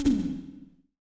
Index of /90_sSampleCDs/Best Service - Real Mega Drums VOL-1/Partition G/SDS V TOM ST